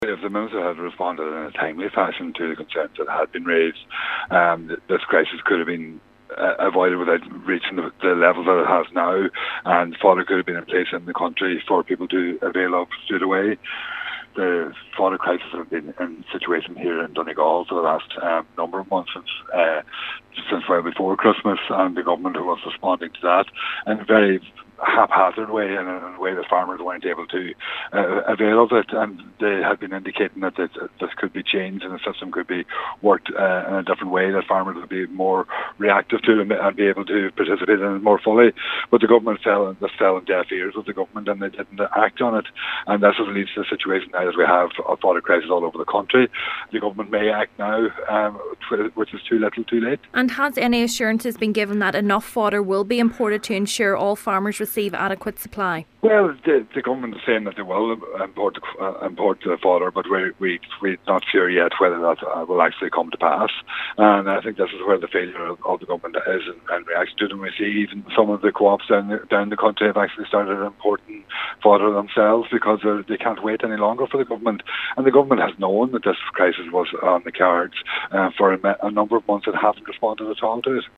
Deputy Pringle says some co-ops have taken action of their own as a result of Government inaction: